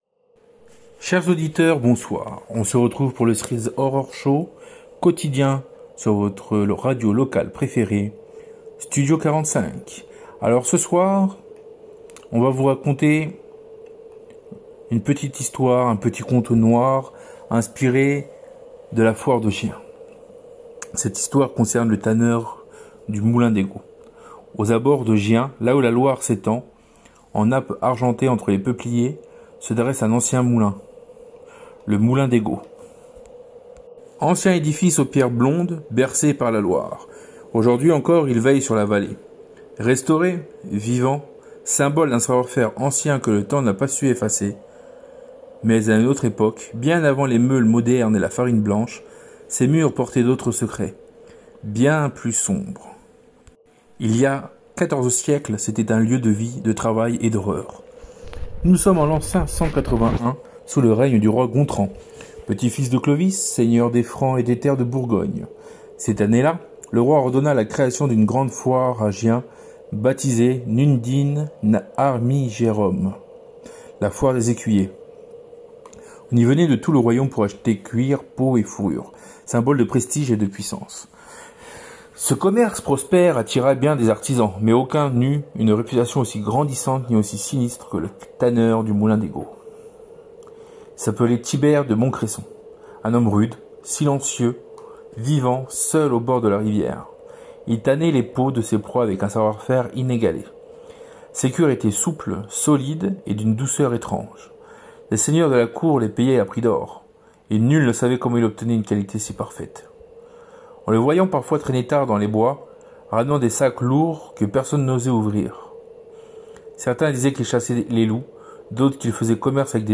Le Moulin des Gaults — Une fiction sonore envoûtante signée Studio 45
Grâce à une mise en son riche et poétique, ce récit invite l’auditeur à ressentir, imaginer et s’abandonner à la magie du son.Une expérience d’écoute intense, entre frisson et émotion, où chaque bruit d’eau, chaque craquement de bois, chaque souffle de vent raconte un fragment d’histoire.